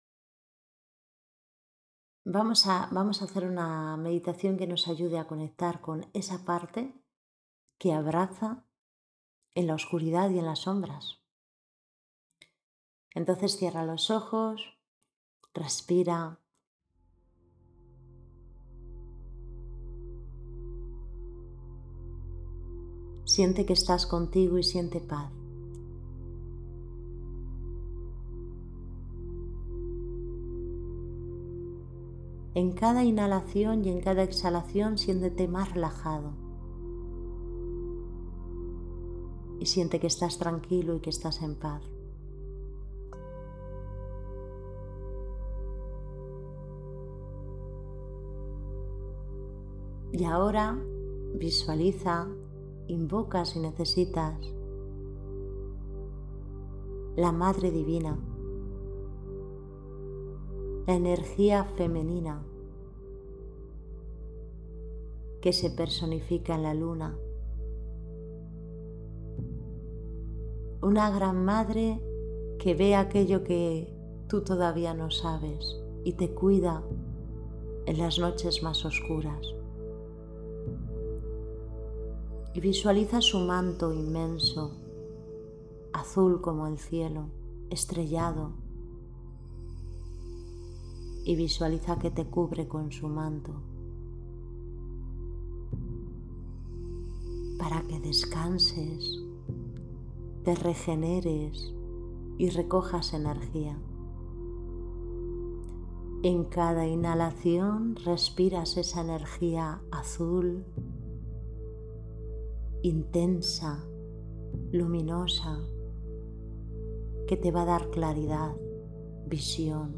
Meditación en la luna creciente
meditacion-en-la-luna-creciente.mp3